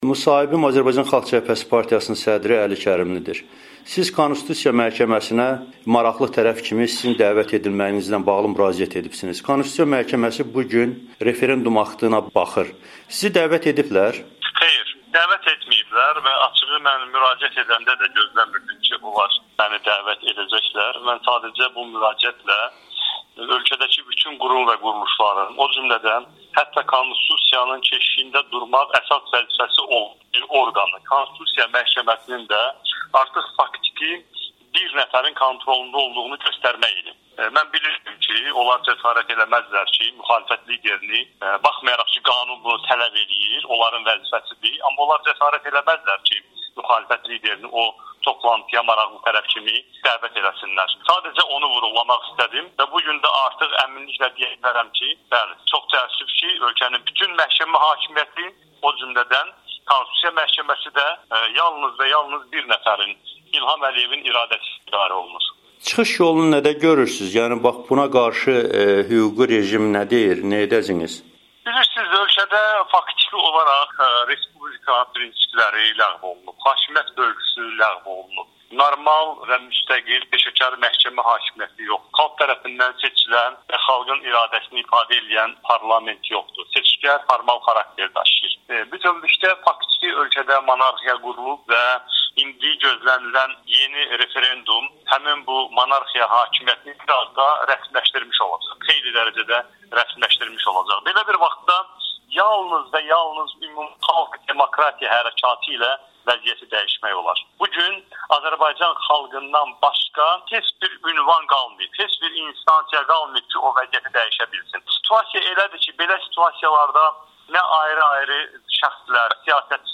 AXCP sədri Əli Kərimlinin Amerikanın Səsinə müsahibəsi